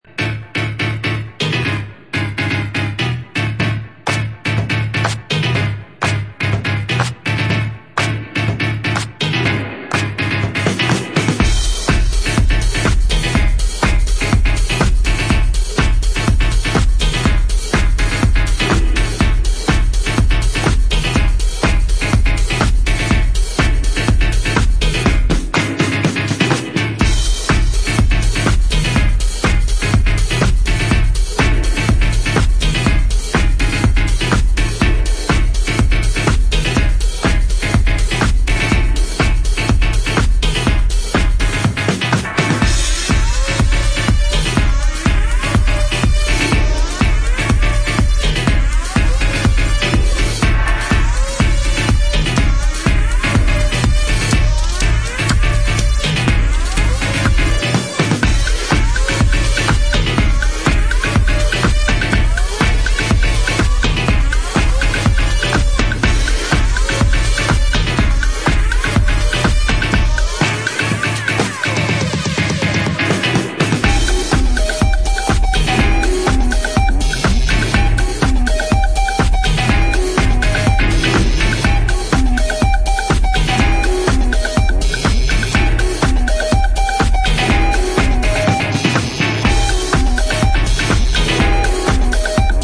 gallic house
90s era rave stabs with the percussive swagger of punk funk